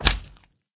sounds / mob / slime